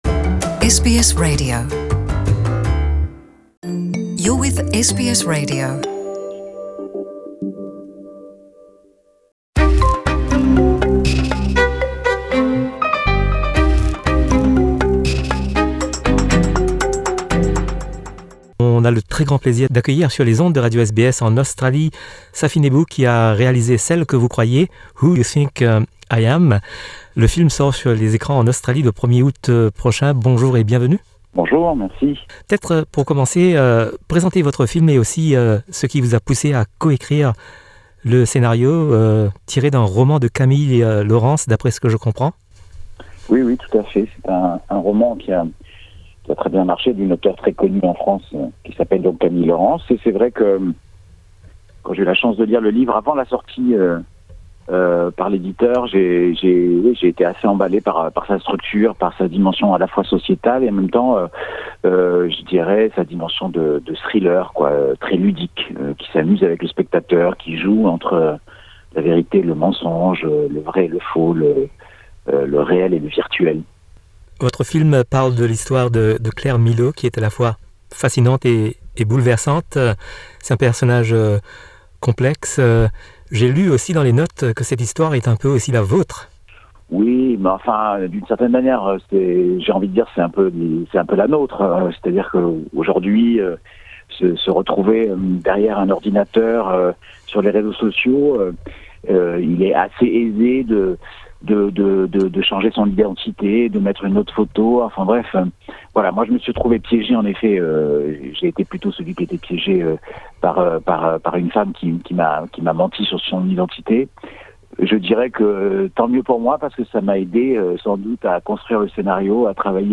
Rencontre avec Safy Nebbou, le réalisateur de “Who you think I am” (Celle que vous croyez ) actuellement sur les écrans en Australie.